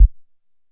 SubImpactSweetener FS048903
Sub Impact Sweeteners; Short And Low Thud Sweetener. - Fight Sweetener